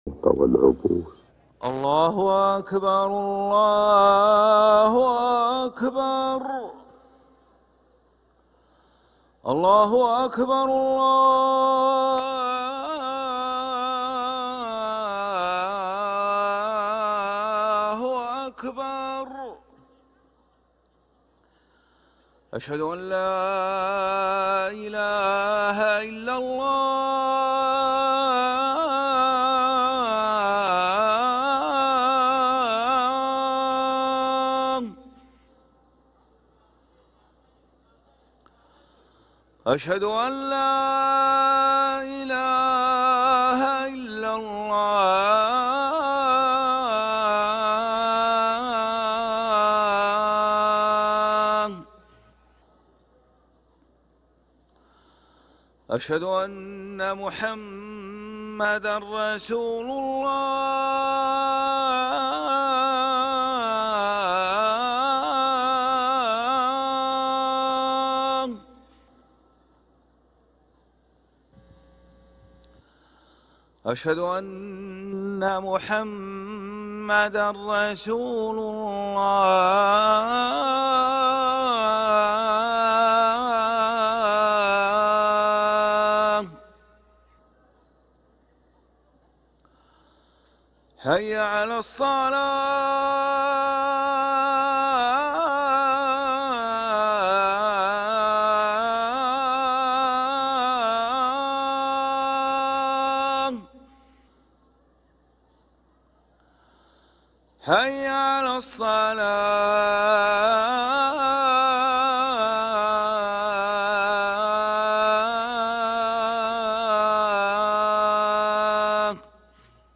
أذان العشاء للمؤذن ماجد العباس الإثنين 23 جمادى الأولى 1446هـ > ١٤٤٦ 🕋 > ركن الأذان 🕋 > المزيد - تلاوات الحرمين